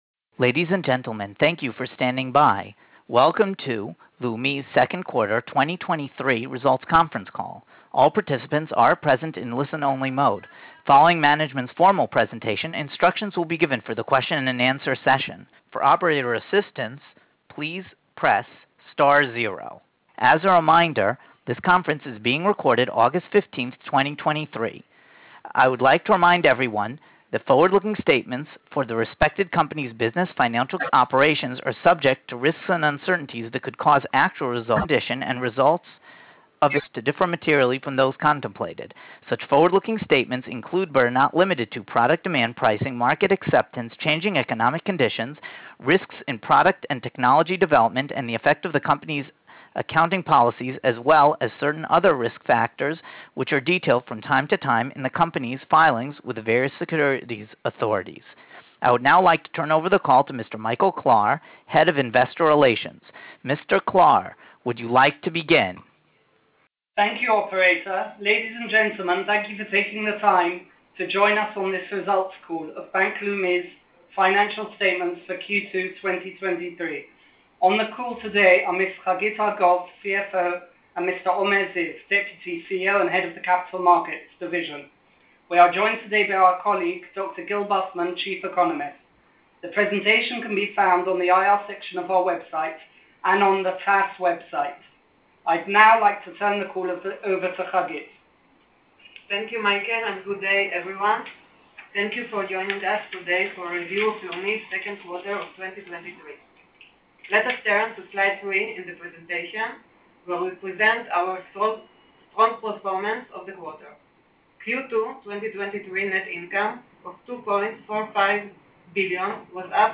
Investor Presentation and Conference Call